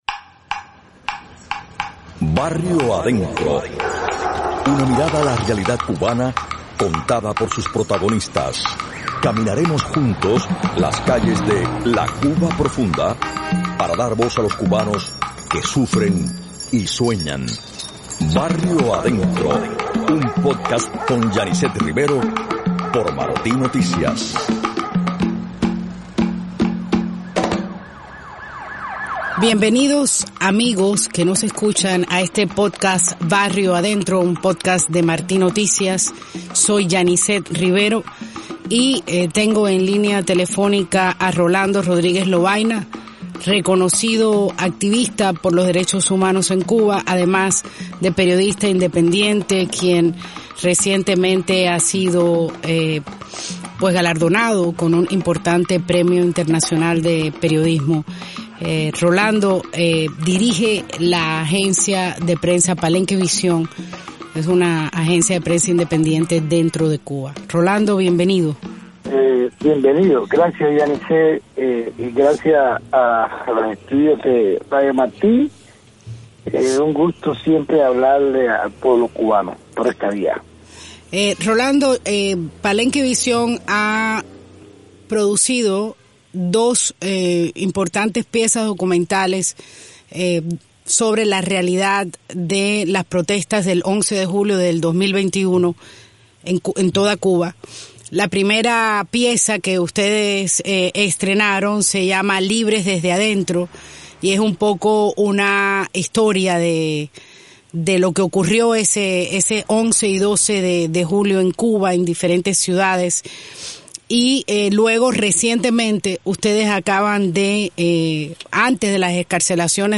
Entrevista al periodista independiente y activista